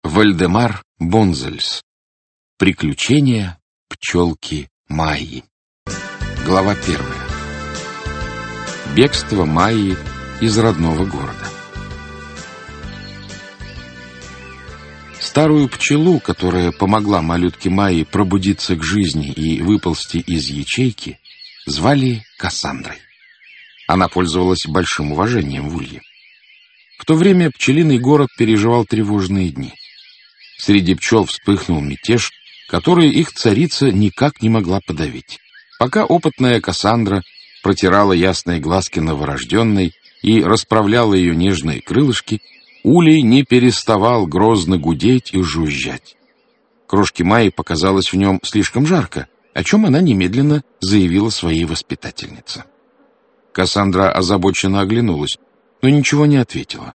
Аудиокнига Приключения пчелки Майи | Библиотека аудиокниг